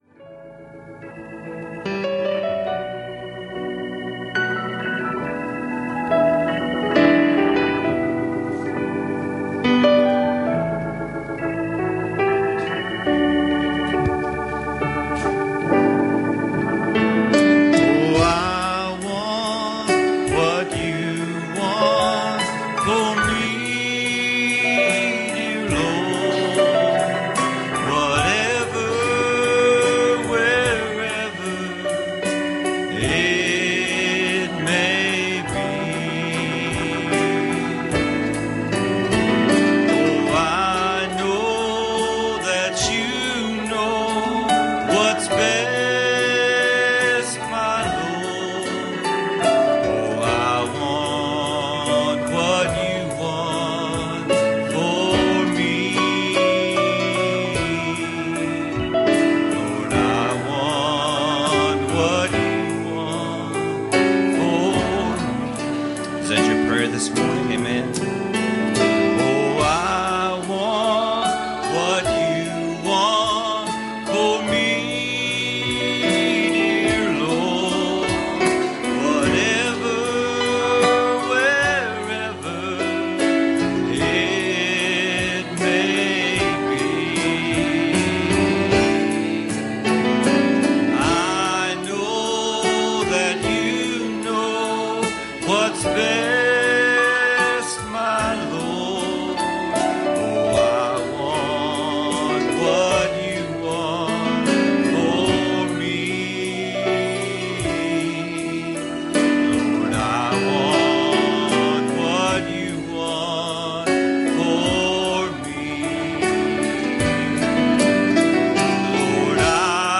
Passage: John 5:14 Service Type: Sunday Morning